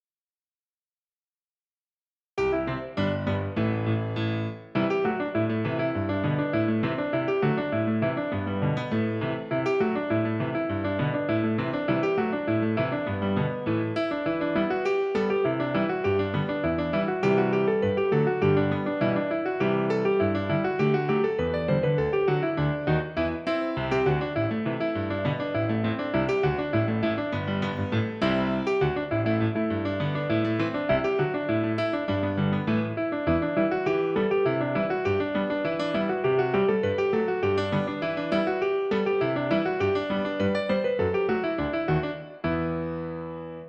Piano accompaniment
Genre Celtic and Gaelic
Tempo 102
Rhythm Reel
Meter 4/4